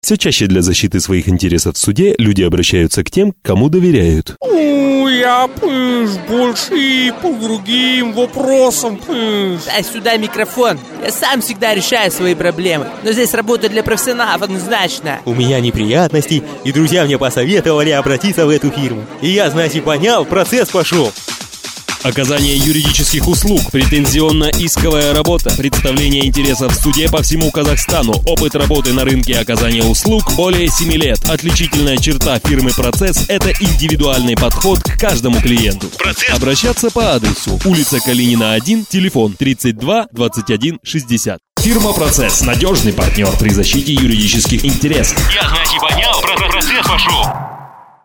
Один из первых моих игровых роликов Категория: Аудио/видео монтаж
Аудио ролик для юридической фирмы "Процесс"